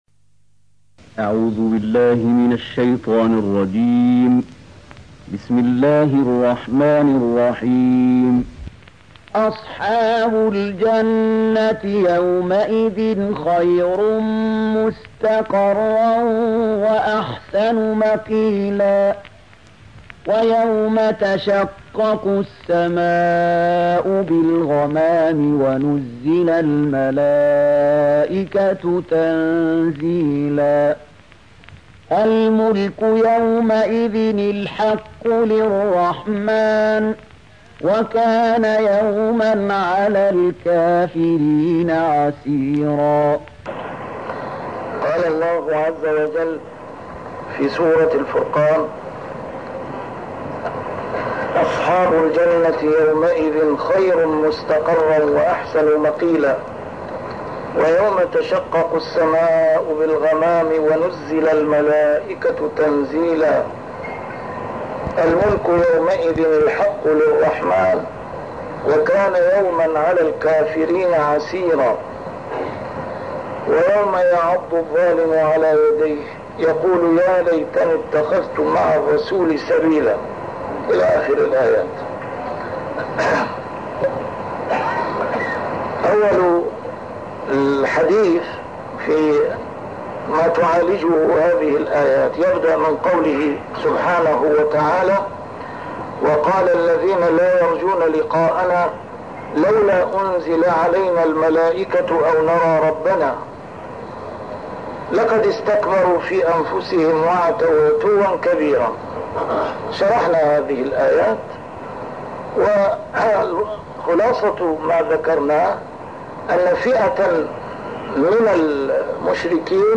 A MARTYR SCHOLAR: IMAM MUHAMMAD SAEED RAMADAN AL-BOUTI - الدروس العلمية - تفسير القرآن الكريم - تسجيل قديم - الدرس 208: الفرقان 24-26